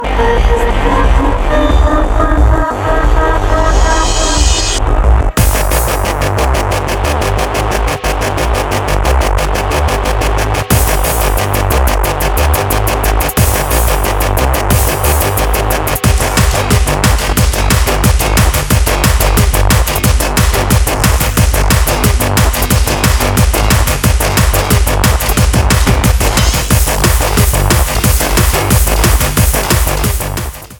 • Genre : hard techno, hard bounce, dark techno, dark trance
Concept of a bitter loud rage about jealousy.